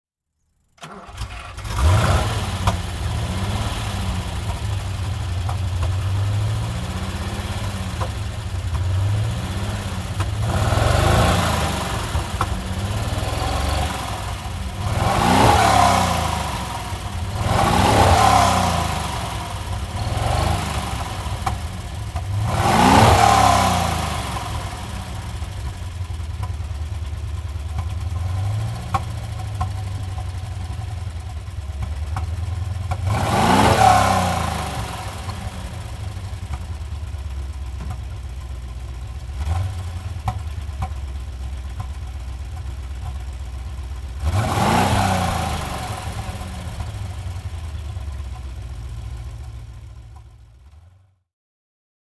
Maserati Bora (1973) - Starten und Leerlauf
Maserati_Bora_1973.mp3